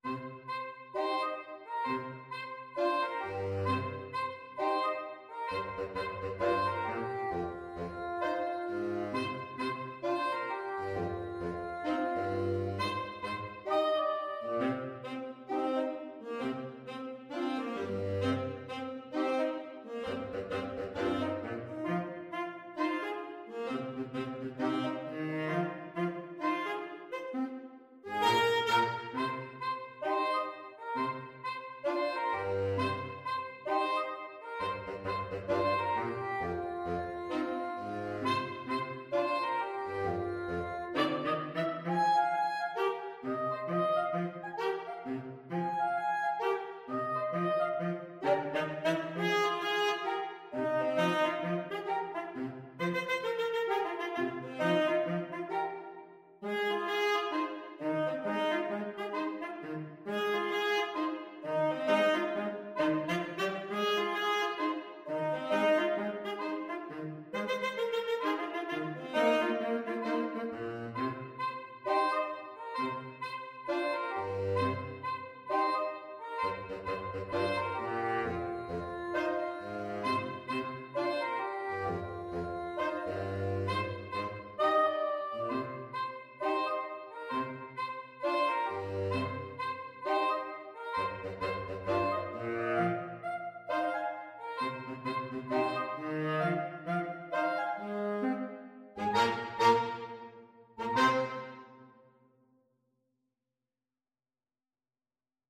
A funky hop-hop style piece.
=132 i=q e K
4/4 (View more 4/4 Music)
Pop (View more Pop Saxophone Quartet Music)